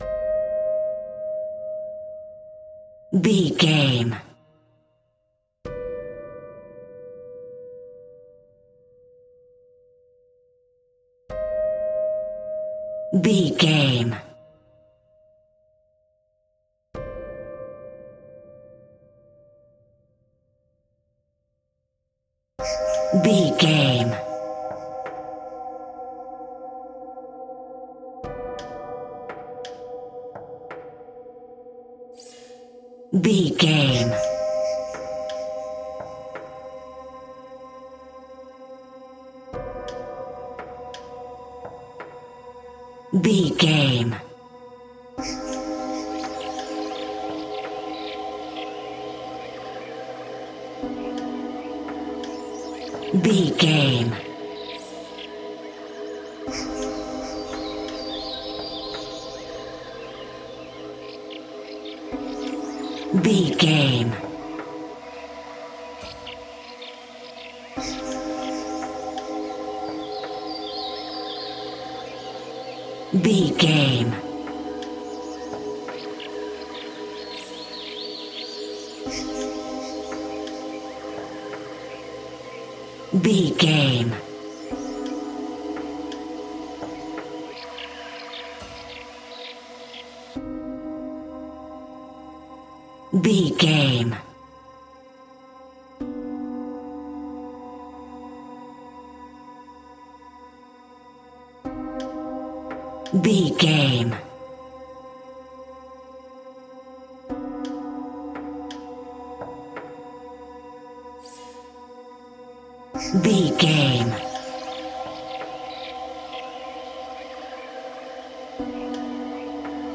Aeolian/Minor
Slow
ominous
dark
eerie
piano
synthesiser
horror music
Horror Pads
Horror Synths